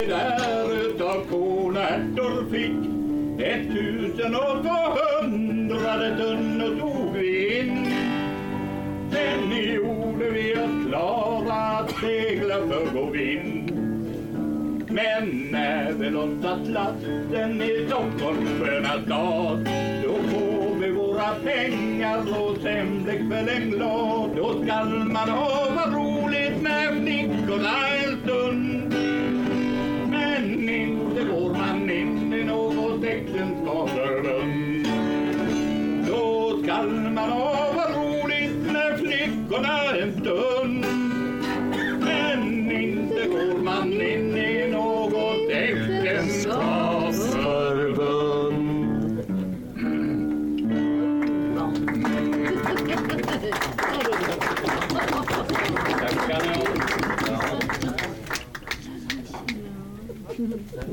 Underhållning till middagen på NRO:s årsmöte i Kungsbacka